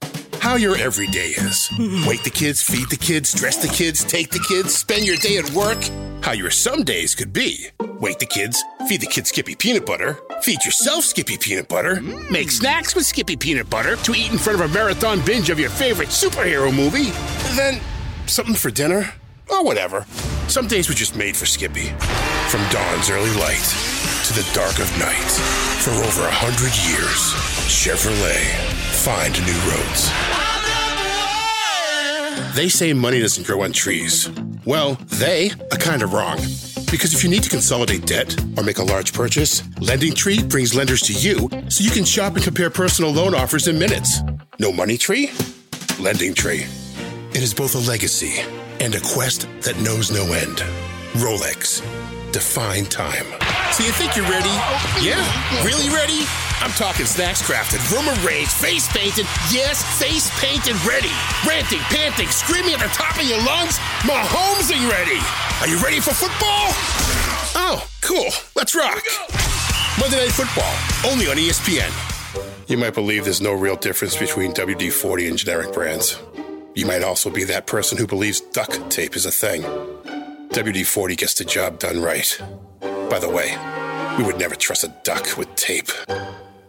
Konversation
Freundlich
Dunkel